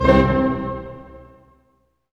Index of /90_sSampleCDs/Roland L-CD702/VOL-1/HIT_Dynamic Orch/HIT_Staccato Oct